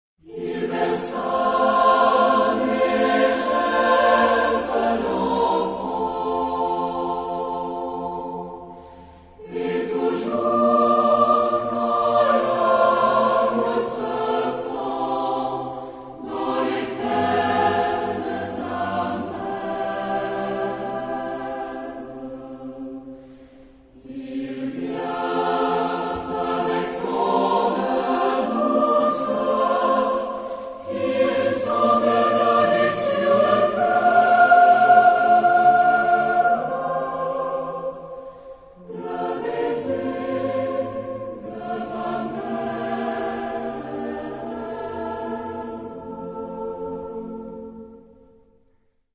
Genre-Style-Forme : Populaire ; Chanson ; Profane
Type de choeur : SATB  (4 voix mixtes )
Tonalité : fa majeur